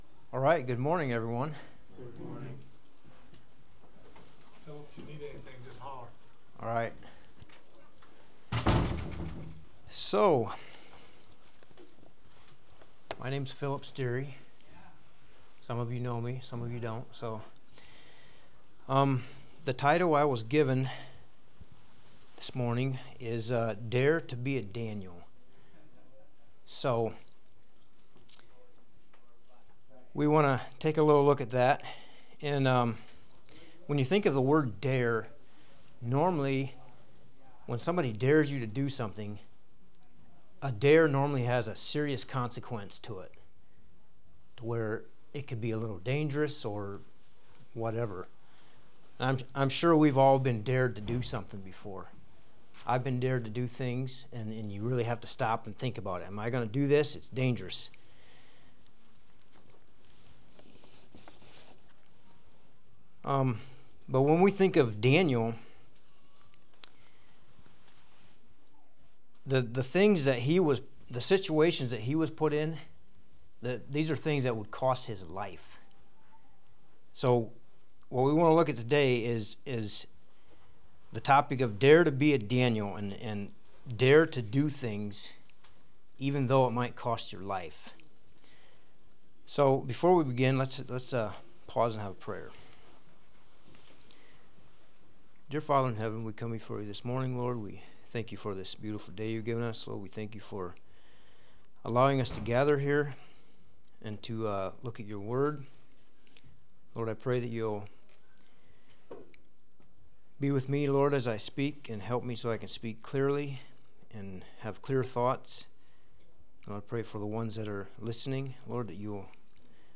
2025 CLE Homeschool Conference